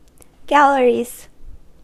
Uttal
Uttal US Okänd accent: IPA : /ˈɡæl.ə.ɹi/ IPA : /ˈɡæl.ɹi/ Ordet hittades på dessa språk: engelska Ingen översättning hittades i den valda målspråket.